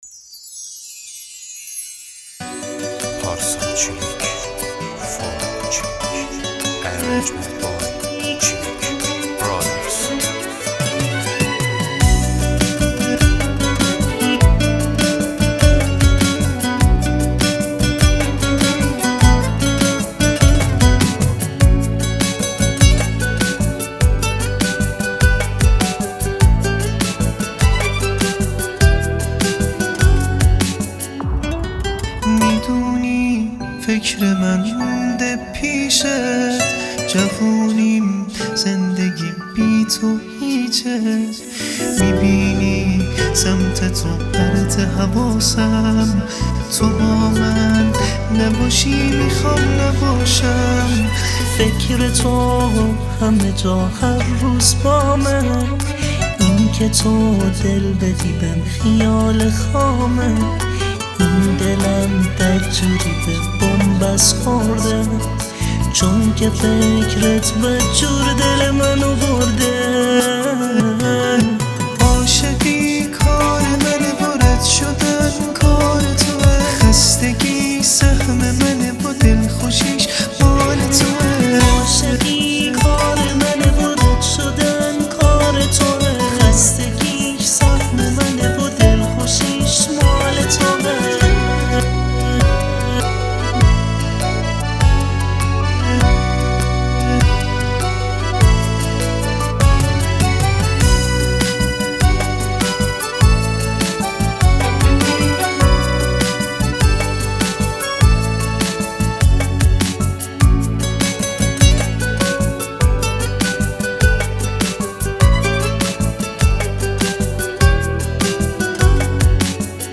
تک آهنگ
گیتار